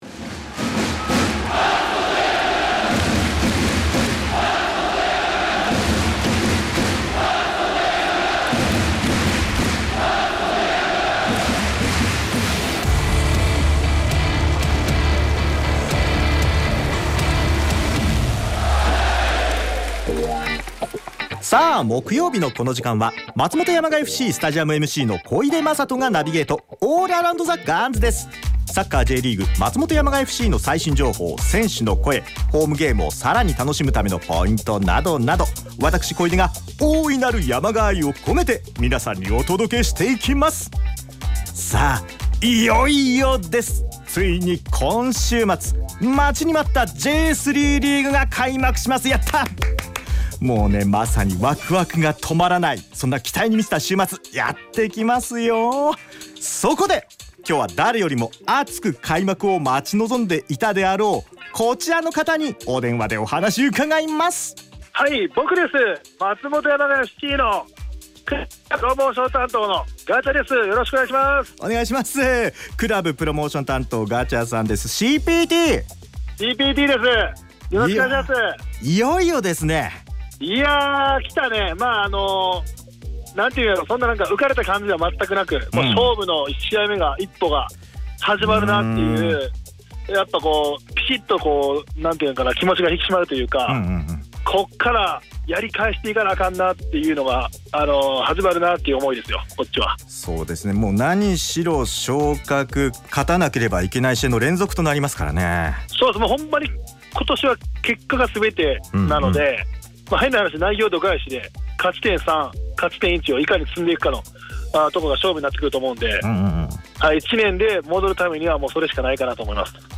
開幕戦に向けて、熱いトークをお届け！